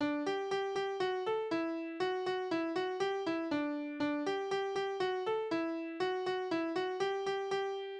Tonart: G-Dur
Taktart: 4/8
Tonumfang: Quinte
Besetzung: vokal
Anmerkung: Vortragsbezeichnung: Polka.